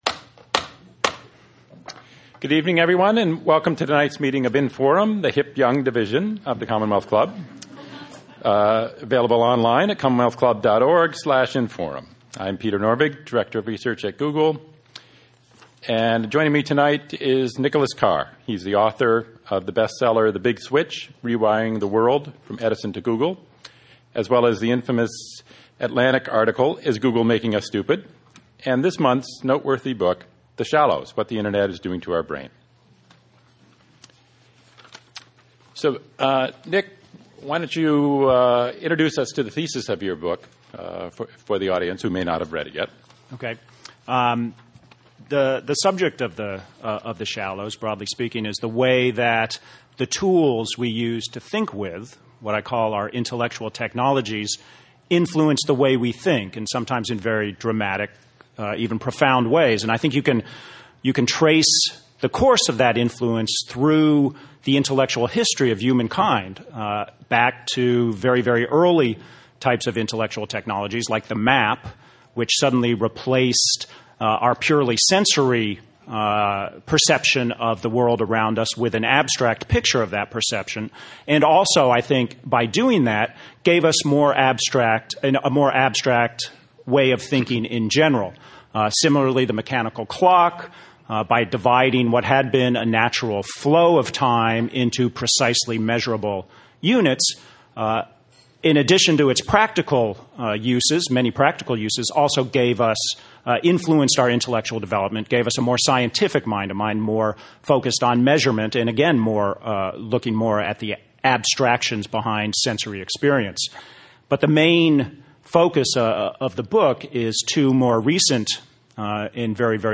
Nicholas Carr, Author, The Shallows In conversation with Peter Norvig, Director of Research, Google